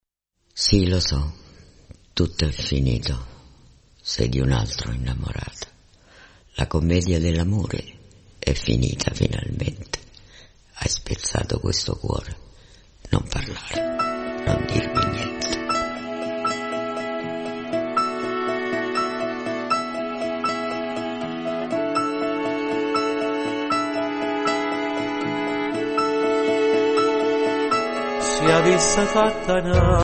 • registrazione sonora di musica